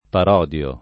vai all'elenco alfabetico delle voci ingrandisci il carattere 100% rimpicciolisci il carattere stampa invia tramite posta elettronica codividi su Facebook parodiare [ parod L# re ] v.; parodio [ par 0 d L o ], -di (alla lat.